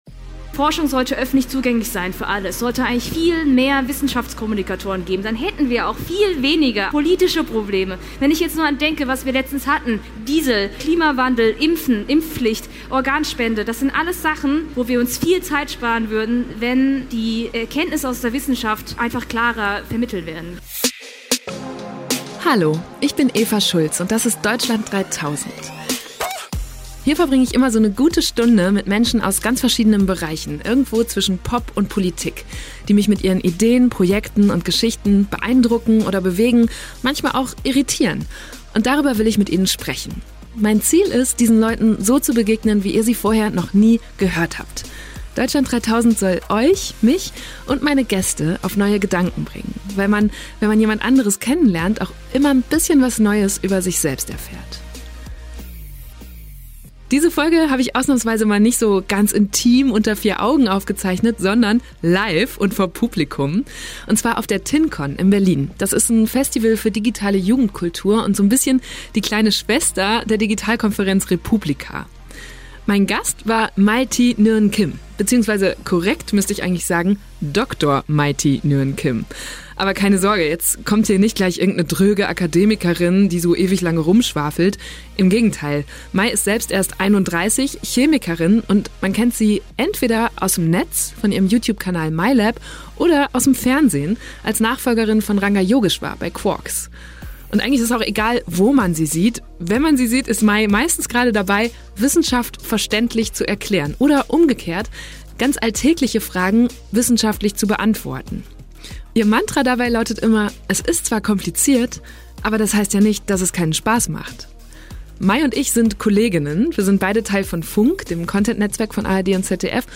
Diese Folge habe ich ausnahmsweise nicht unter vier Augen aufgezeichnet, sondern live vor Publikum auf der Tincon in Berlin. Das ist ein Festival für digitale Jugendkultur und quasi die kleine Schwester der Digitalkonferenz re:publica. Mein Gast war Dr. Mai Thi Nguyen-Kim.